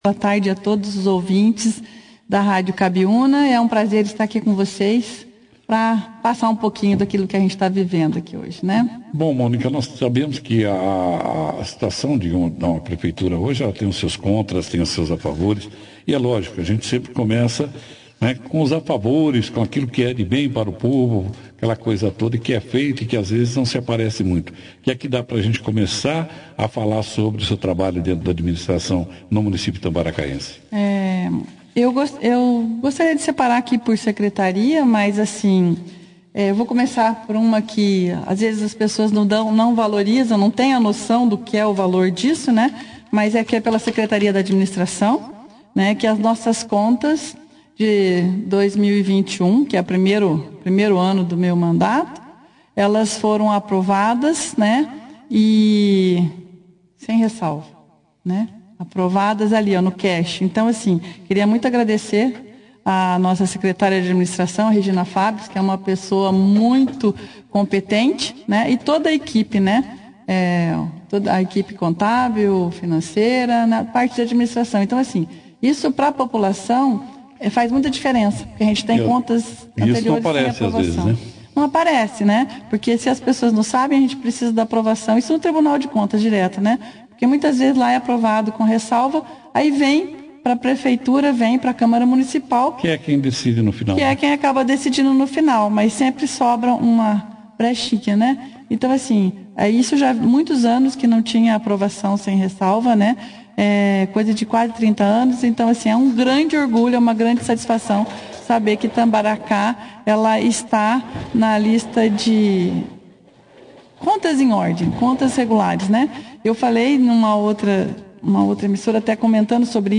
Prefeita de Itambaracá, Mônica Zambon, fala sobre o trabalho de sua administração e os problemas enfrentados por ela - Rádio Cabiuna
A prefeita da cidade de Itambaracá, Mônica Cristina Zambon Holzmann, (foto), participou da edição deste sábado, 22/04, do jornal Operação Cidade, falando sobre o trabalho de sua administração e os problemas enfrentados por ela.